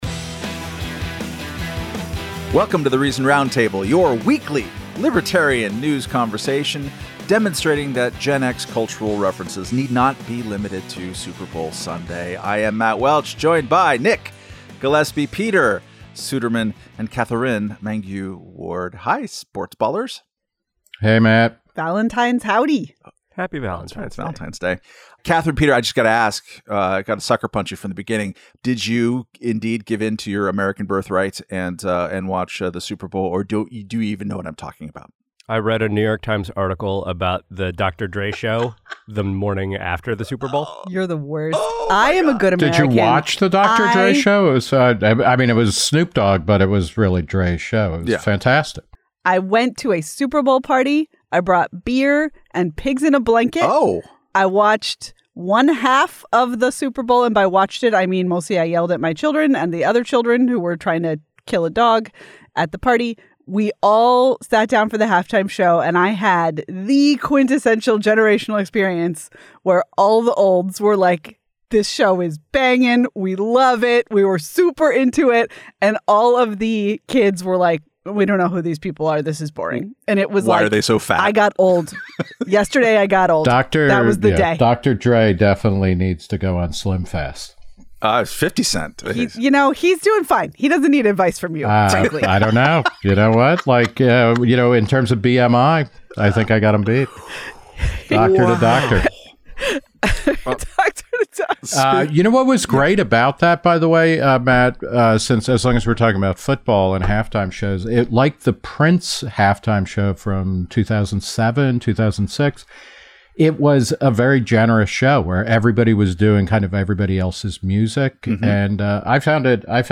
Plus, the editors' takes on the Super Bowl.